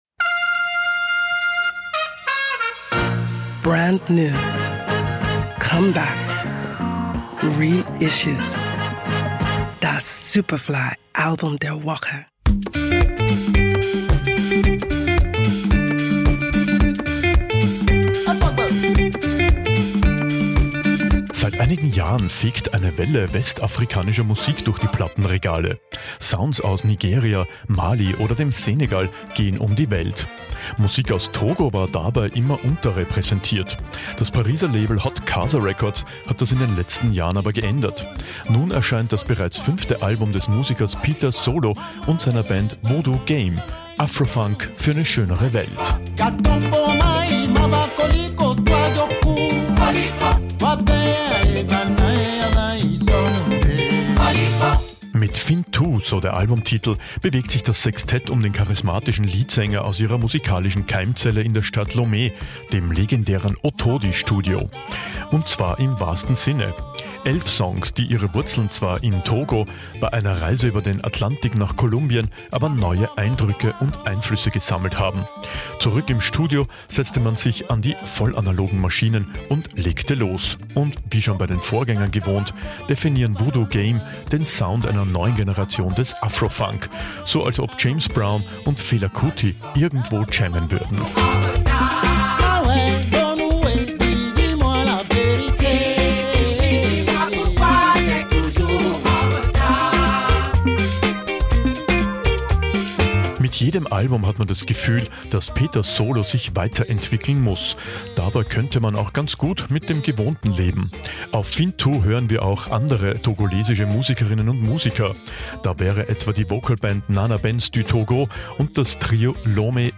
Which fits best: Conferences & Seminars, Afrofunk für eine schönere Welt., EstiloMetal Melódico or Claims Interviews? Afrofunk für eine schönere Welt.